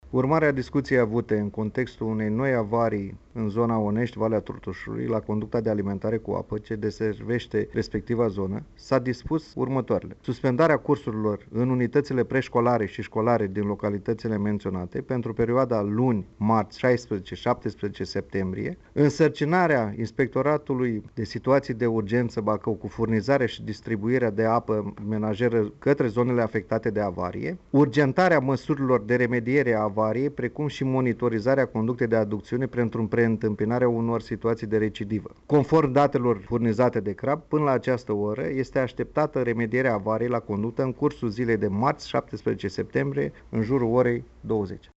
Prefectul judeţului Bacău, Valentin Ivancea: